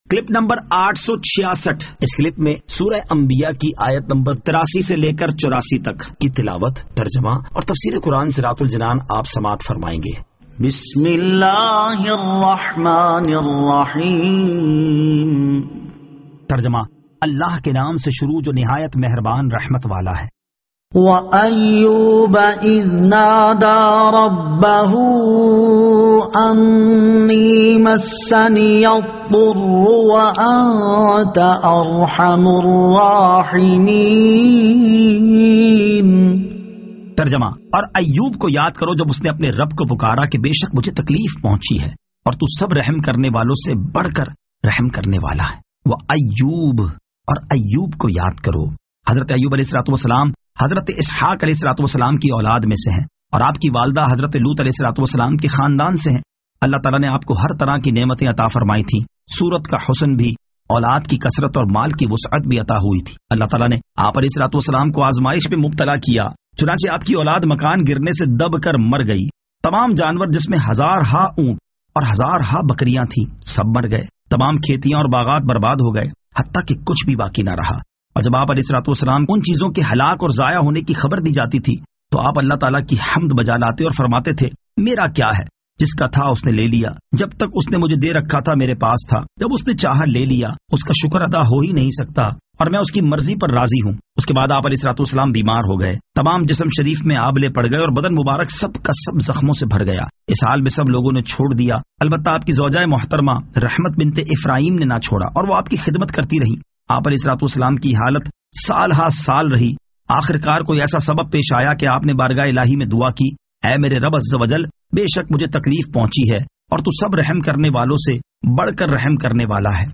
Surah Al-Anbiya 83 To 84 Tilawat , Tarjama , Tafseer